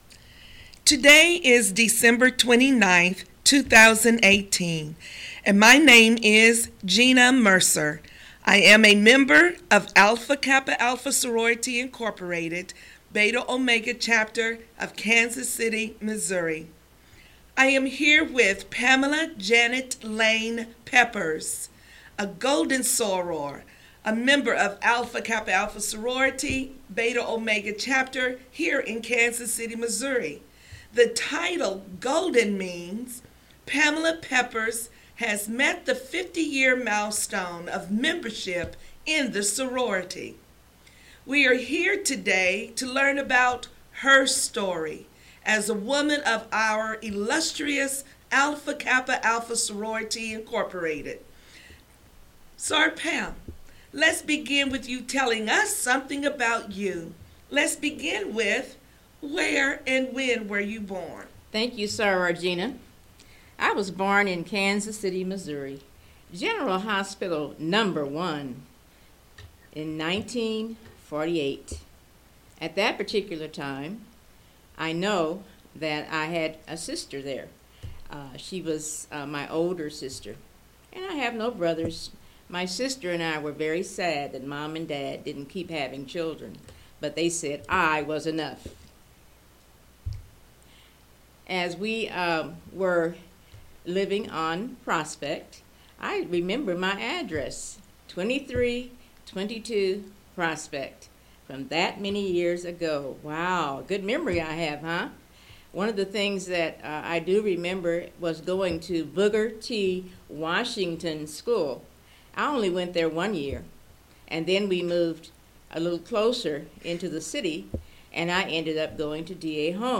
Oral History Alpha Kappa Alpha